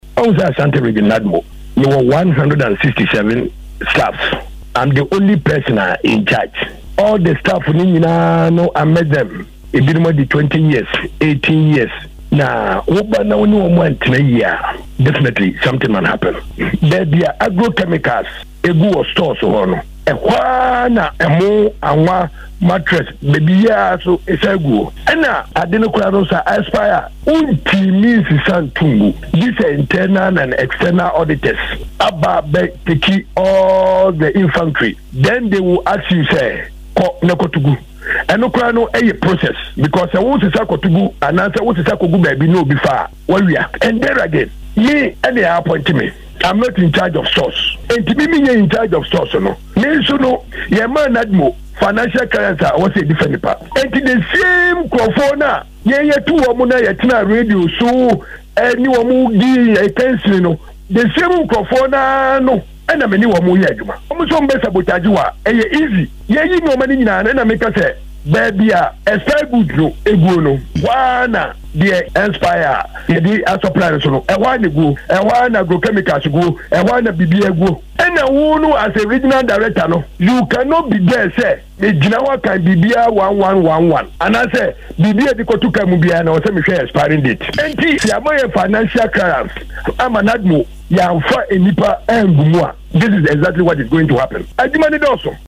Speaking in an interview with Otec News in Kumasi on Thursday, September 27, 2018, the Assembly Member for Dichemso Electoral Area in Kumasi, Hon. Kwabena Nsenkyire stated that NADMO need financial clearance from the Ministry of Finance as a matter of urgency to employ more people to ensure effectiveness in discharging their duties.